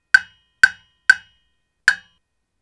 • jam block studio sample 2.wav
jam_block_studio_sample_2_Eu6.wav